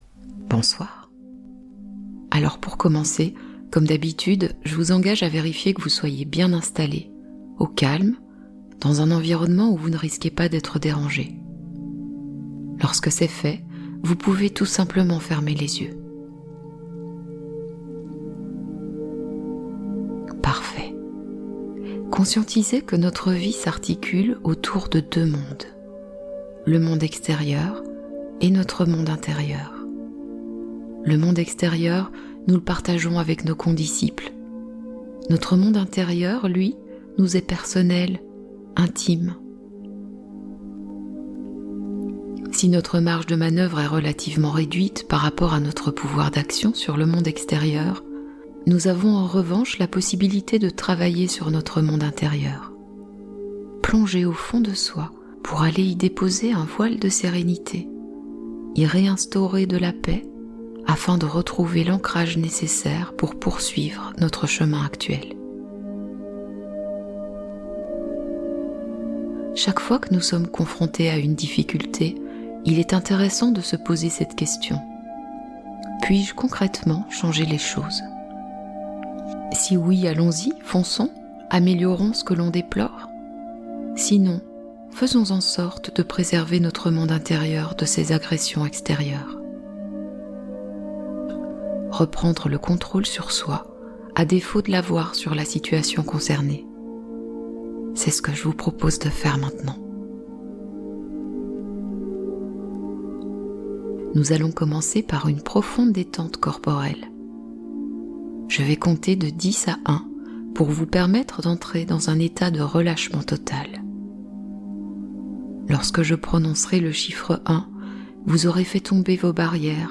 Cocon de sécurité nocturne : Dormez protégé et bercé | Hypnose douceur et bien-être absolu